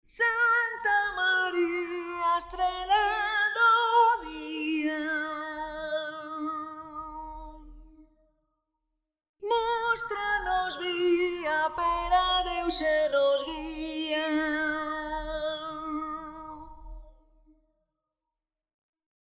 Frequenzschieber werden immer wieder gerne für Stimmverfremdungen eingesetzt, hier einige Beispiele: